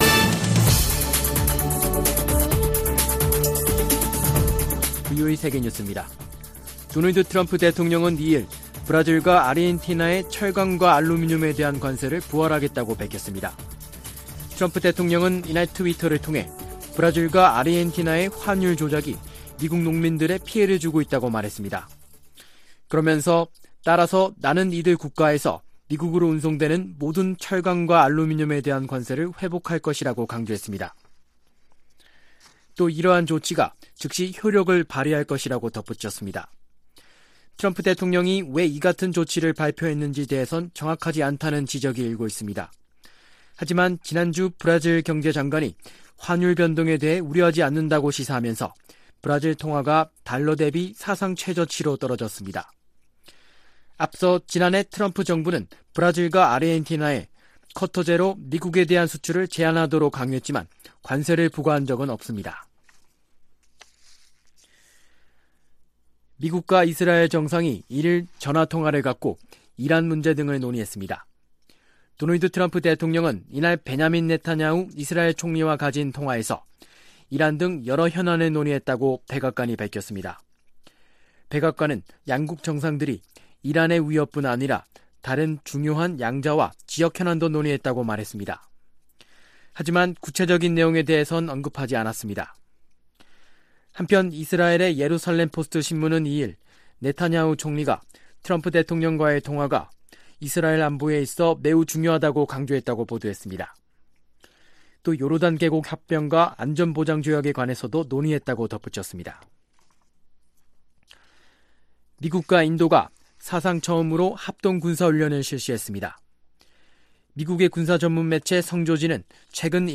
VOA 한국어 아침 뉴스 프로그램 '워싱턴 뉴스 광장' 2018년 12월 3일 방송입니다. 최근 미군 정찰기의 한반도 상공비행이 잇따르고 있습니다.